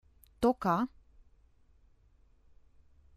toca